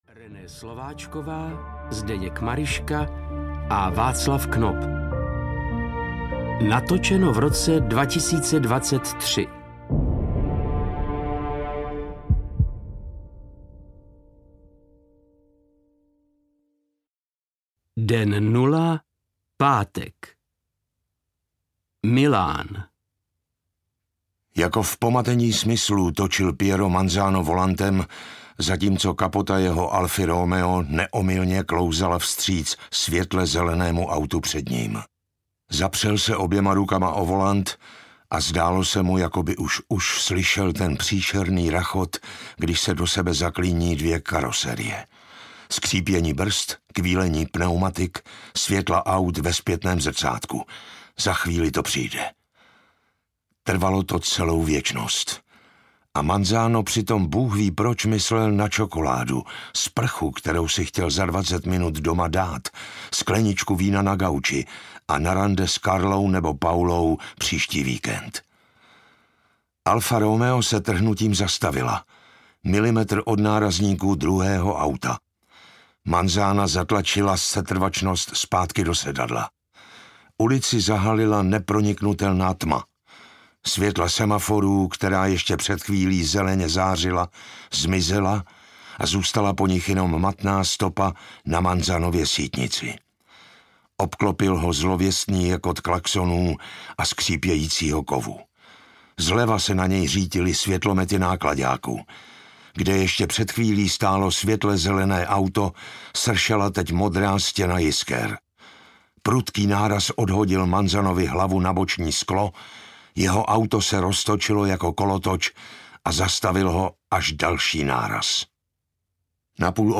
Blackout audiokniha
Ukázka z knihy